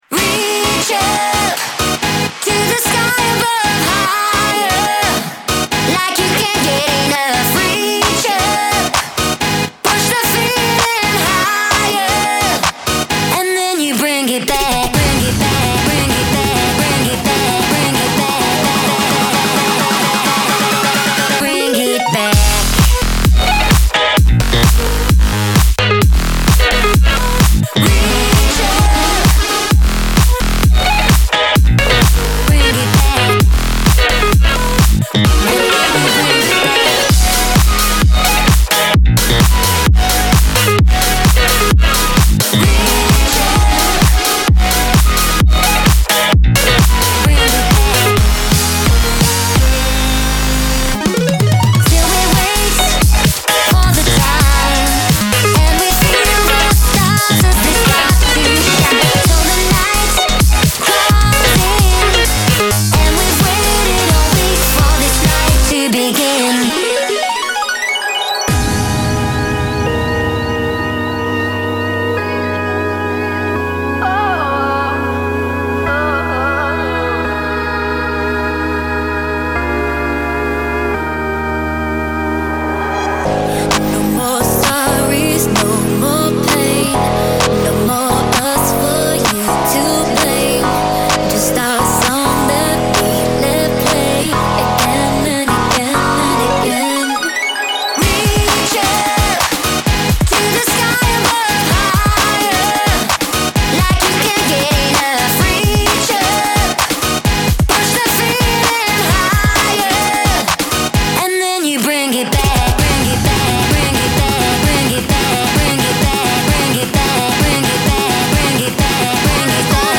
BPM130-130
Audio QualityPerfect (High Quality)
Future Bass song for StepMania, ITGmania, Project Outfox
Full Length Song (not arcade length cut)